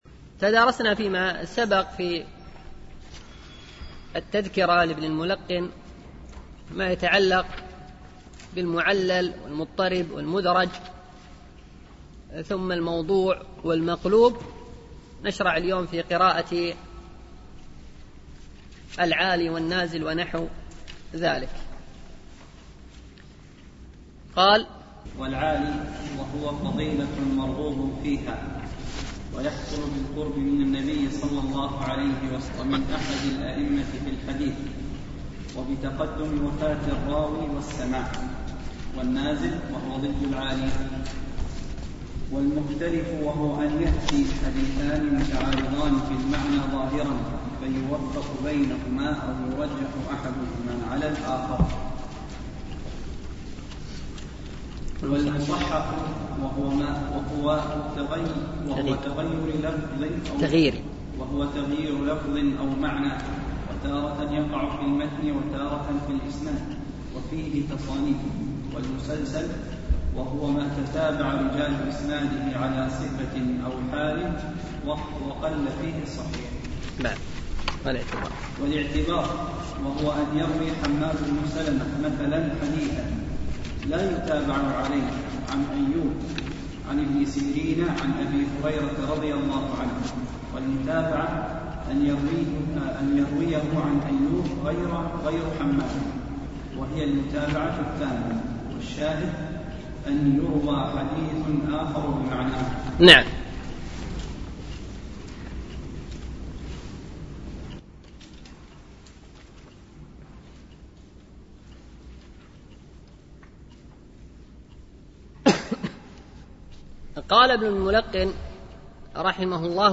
شرح التذكرة في علوم الحديث ـ الدرس الثامن